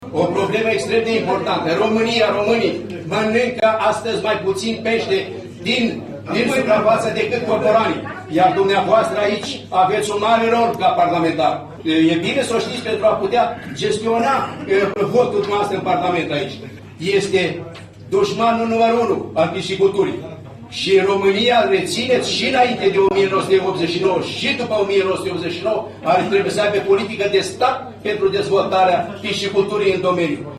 Românii mănâncă astăzi mai puţin peşte decât cormoranii, însă Ministerul Agriculturii nu dă bani pentru cormorani, deşi sunt duşmanul numărul unu al pisciculturii, a declarat, marţi, ministrul de resort, Petre Daea, în comisiile reunite pentru buget, finanţe şi bănci din Parlament.
Petre Daea i-a răspuns deputatului pe un ton ridicat şi iritat, totodată, gesticulând din deget: “Nu. Nu. Nu dăm bani pentru cormorani, să ştiţi, vă spune ministrul Daea. V-a intrat cormoranul în cap? Înseamnă că a avut loc.”